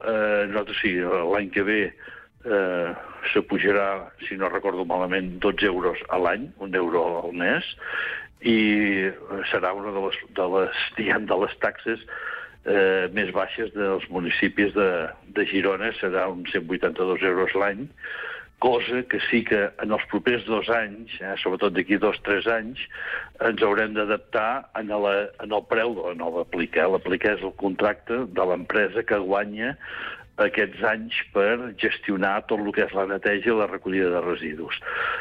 En una entrevista al programa Supermatí de Ràdio Capital i Ràdio Palafrugell, l’alcalde de Torroella de Montgrí i l’Estartit, Jordi Colomí, ha fet un balanç de la situació actual del municipi.